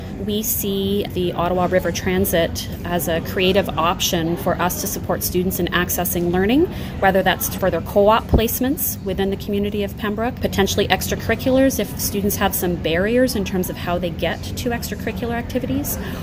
At a press conference held at Algonquin College